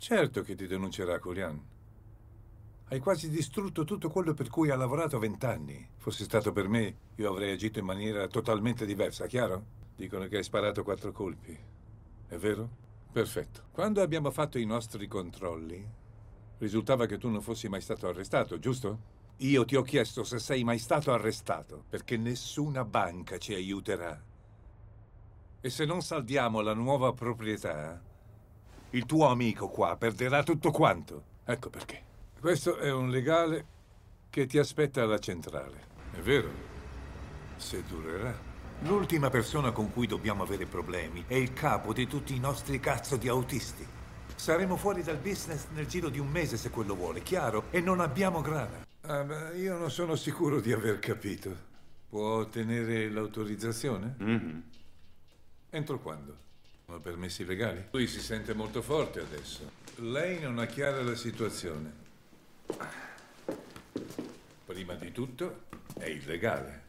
FILM CINEMA